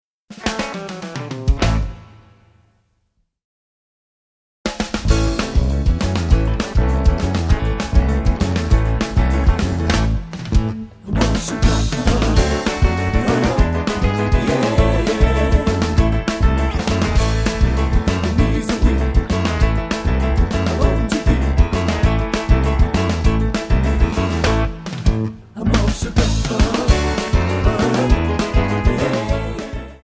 Backing track files: 1950s (275)
Buy With Backing Vocals.